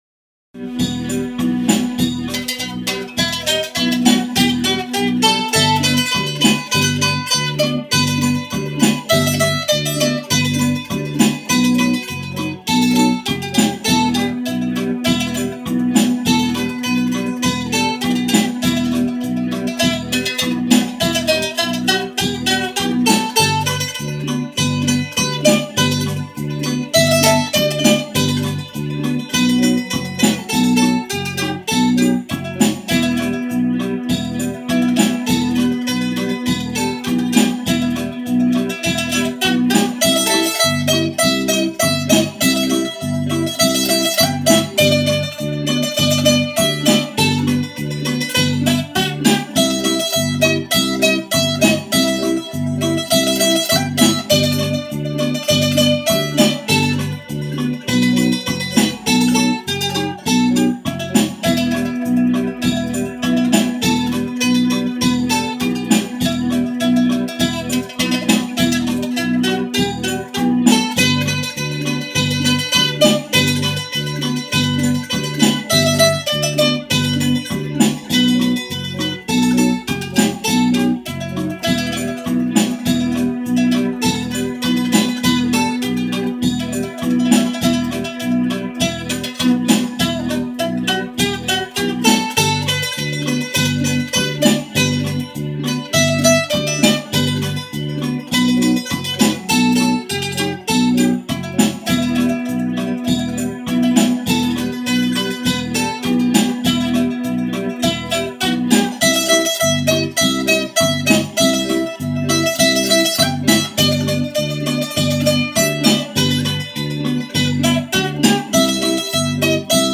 Hopp: egy mandolin!